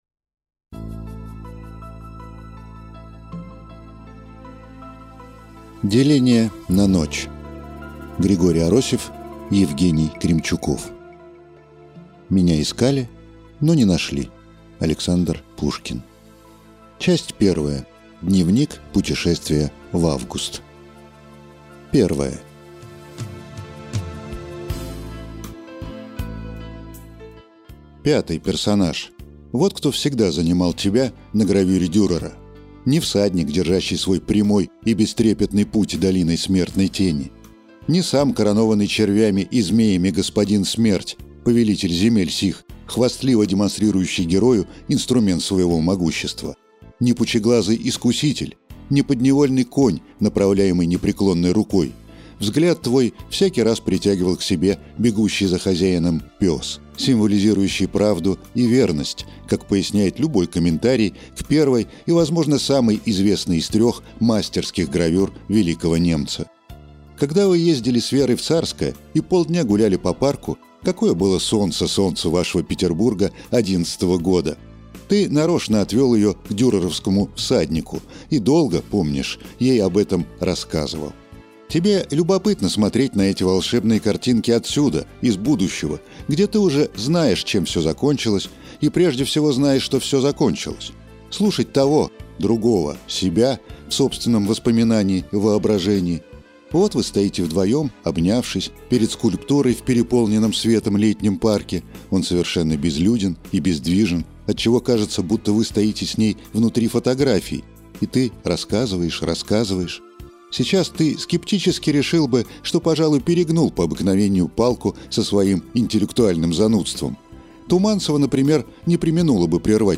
Аудиокнига Деление на ночь | Библиотека аудиокниг